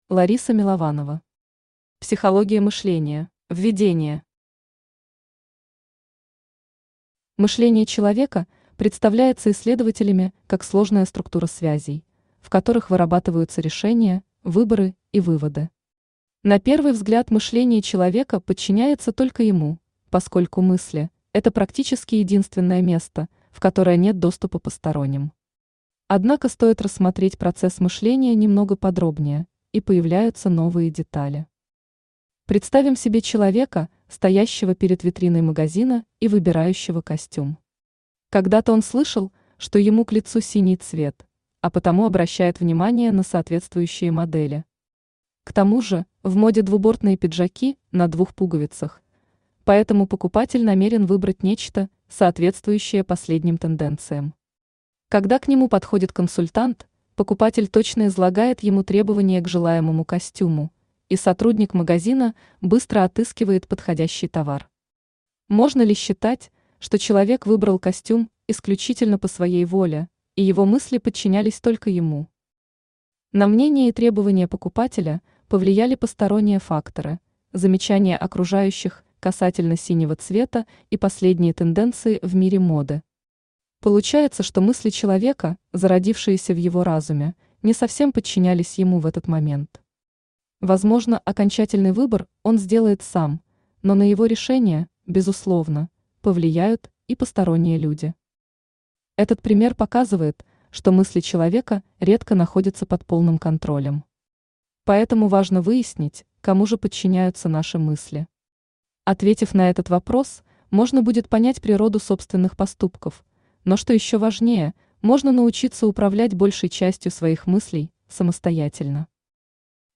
Aудиокнига Психология мышления Автор Лариса Александровна Милованова Читает аудиокнигу Авточтец ЛитРес.